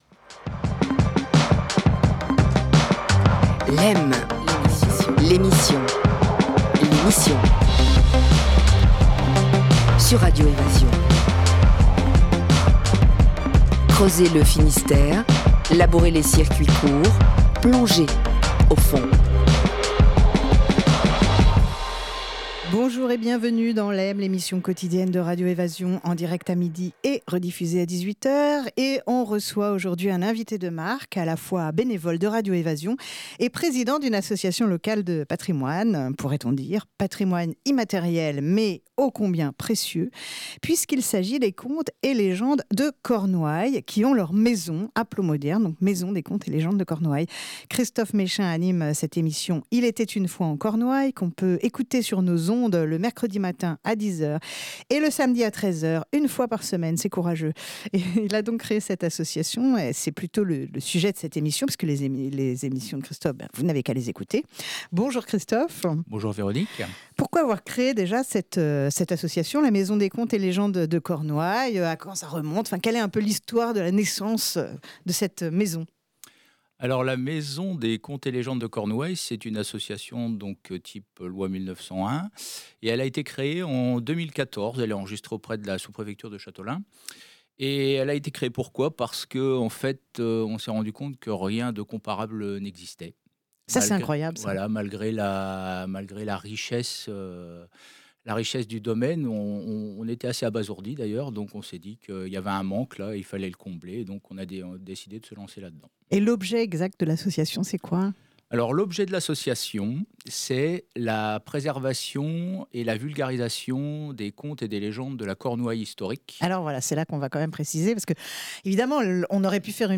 Réécoutez l'émission